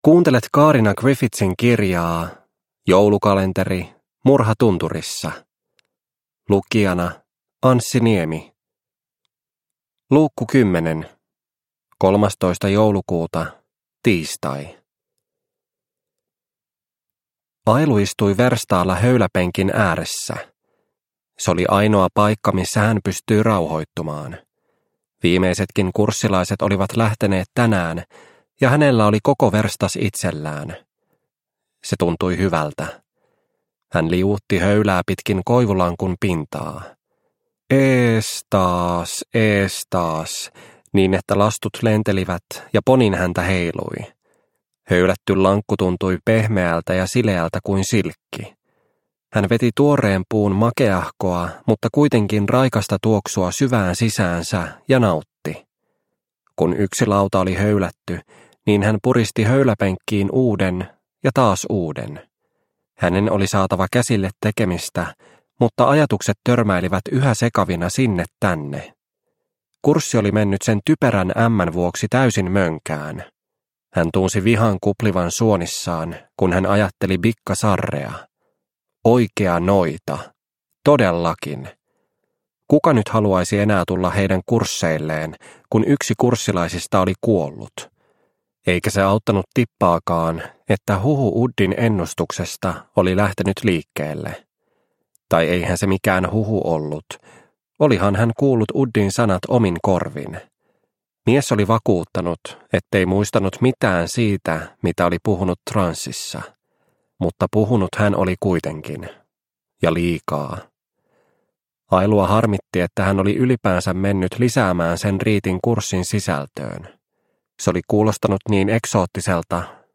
Murha tunturissa - Osa 10 – Ljudbok – Laddas ner